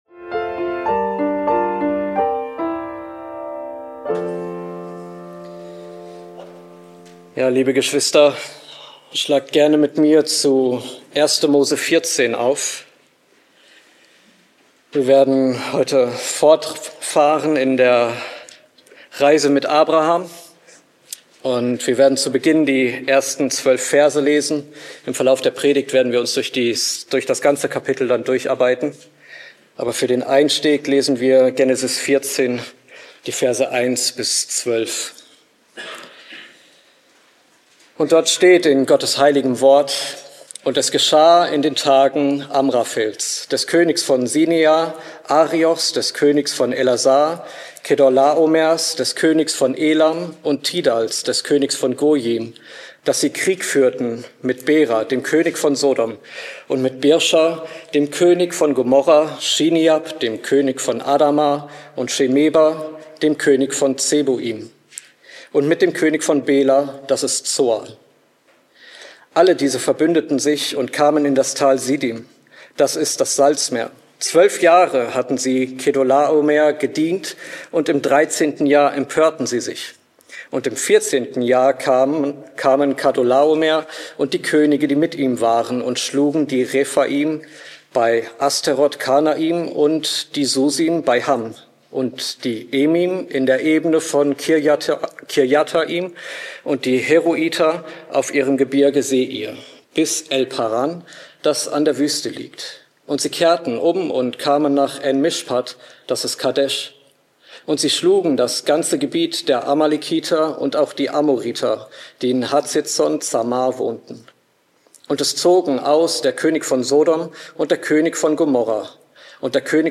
## Details Diese Predigt behandelt Genesis 14 in vier Hauptabschnitten und zeigt Abrahams geistliche Reifung inmitten weltlicher Konflikte.